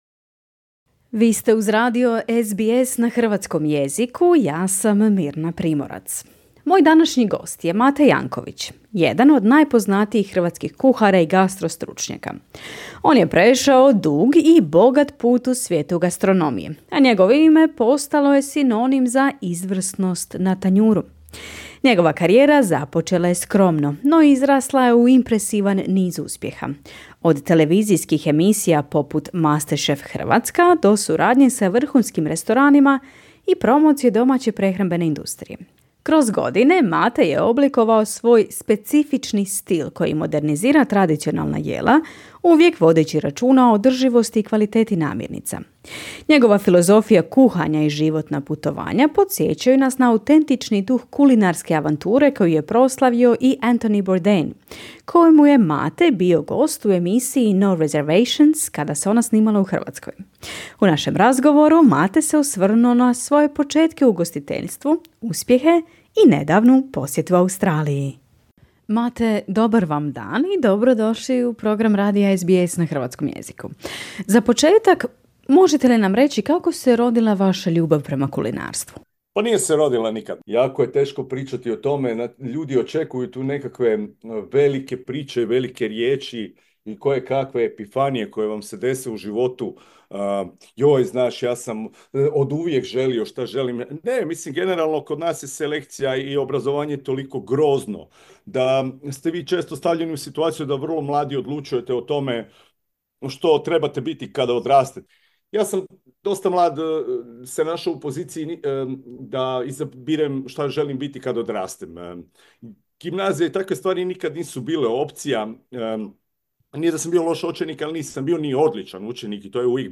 U razgovoru za SBS Croatian, Mate se osvrnuo na svoje početke u ugostiteljstvu, uspjehe, i nedavnu posjetu Australiji.